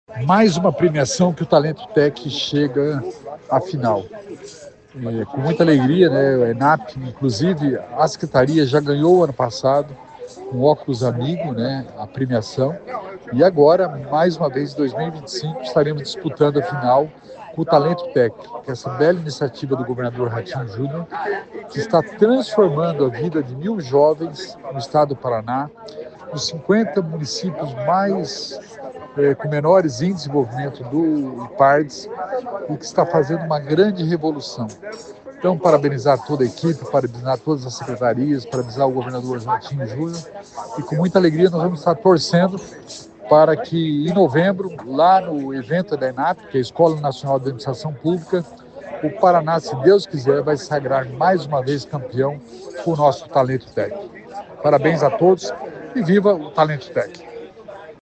Sonora do secretário da Inovação e Inteligência Artificial, Alex Canziani, sobre o Talento Tech finalista em prêmio nacional